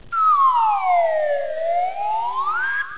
00158_Sound_yoyo.mp3